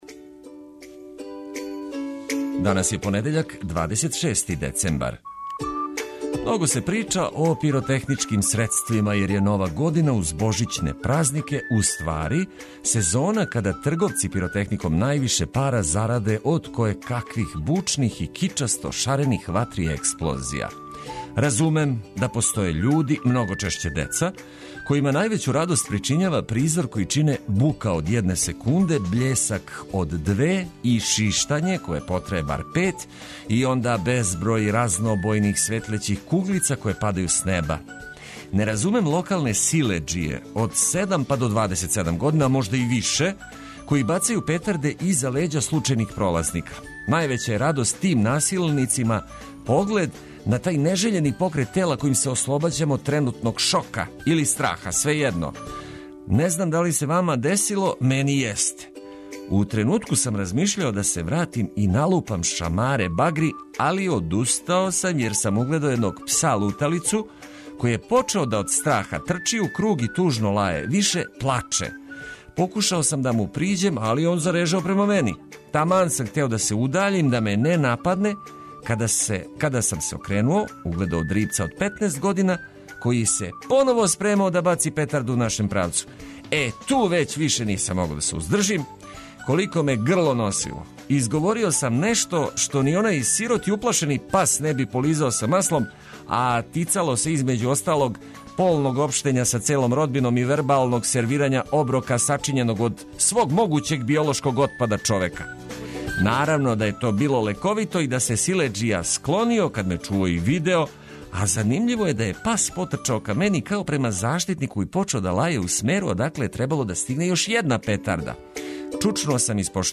Почетак последње седмице у овој старој години обележиће занимљиве и корисне приче уз ведру музику којом ћемо лакше победити поспаност.